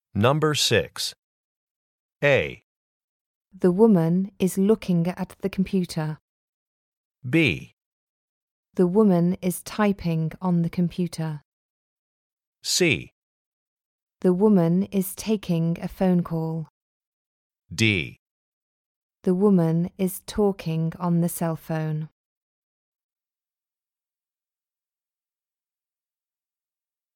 For each question in this part, you will hear four statements about a picture in your test book.
The statements will not be printed in your test book and will be spoken only one time.